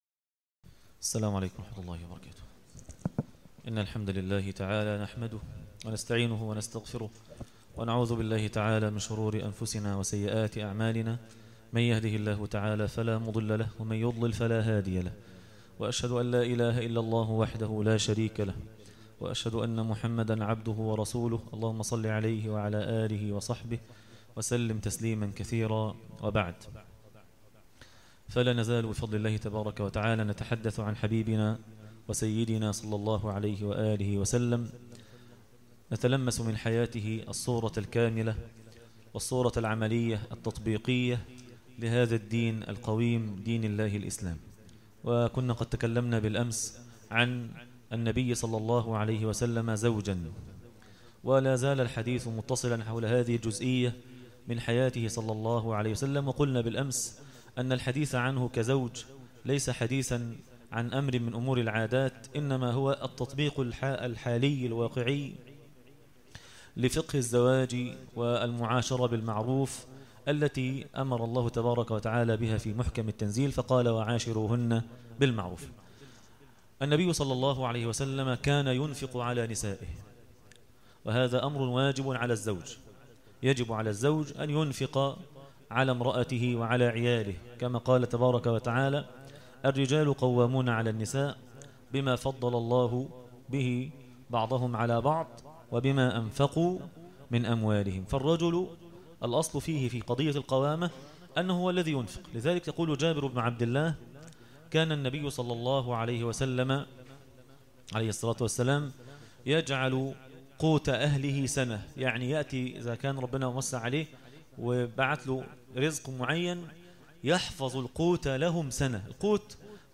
النبي (صلي الله عليه وسلم ) زوجا - الجزء الثاني- درس التراويح ليلة 23 رمضان 1437هـ